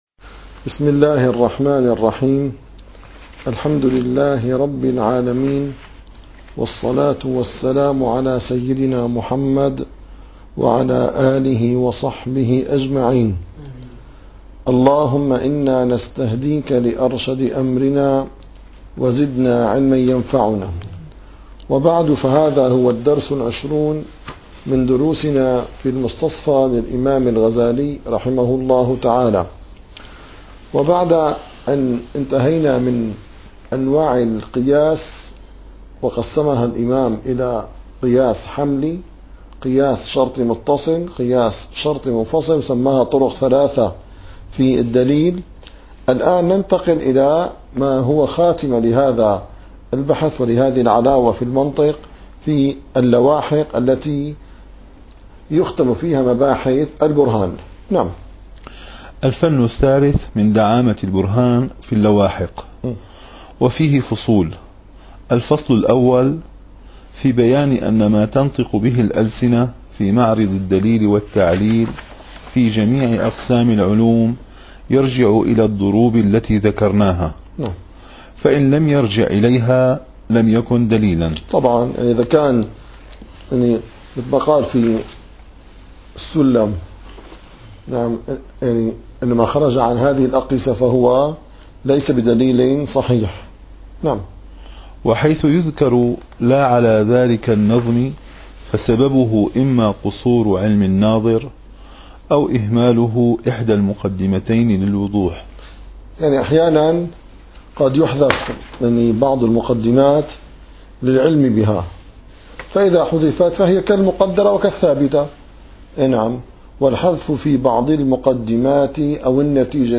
- الدروس العلمية - المستصفى - 20- الفن الثالث من دعامة البرهان في اللواحق